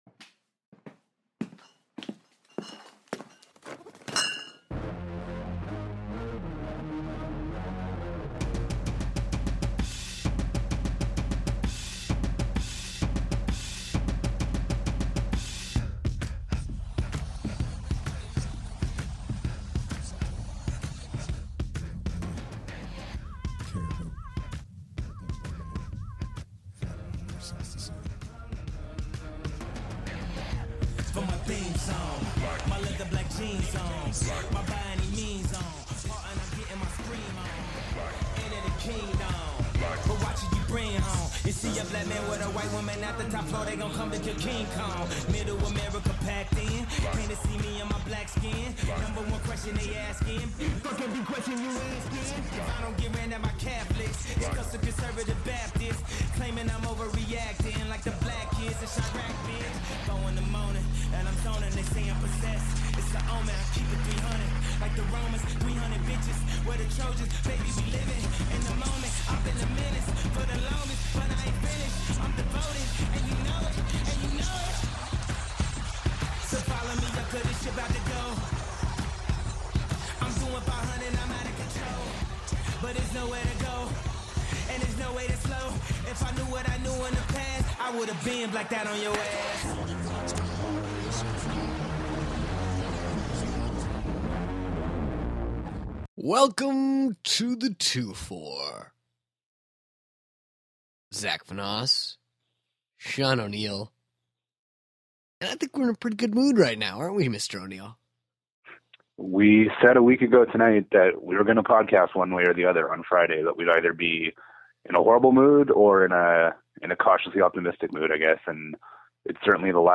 Apologies off the top for the mic breaking in this one, but we soldiered on. It’s all Team Canada talk this time out, with a little of the other teams, other sports and everything else Sochi related thrown in. With the US now in the rear-view it all boils down to Canada and Sweden with gold on the line.